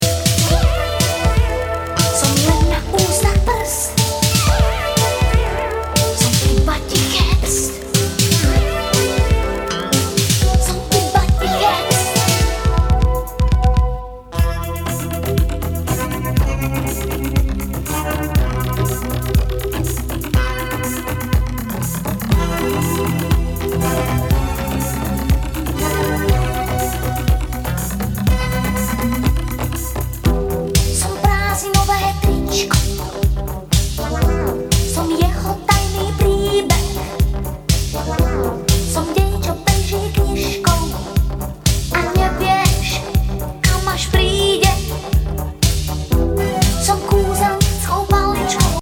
ボーカル・エレクトリック・ディスコ作!コズミック・スムース・ディスコ・ナンバー